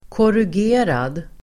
Ladda ner uttalet
korrugerad.mp3